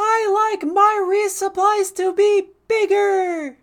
DRG-Femboy-Voice